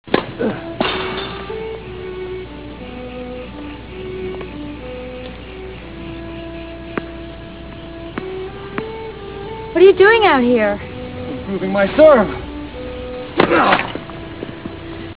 Comment: instrumental